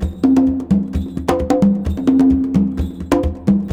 CONGABEAT4-R.wav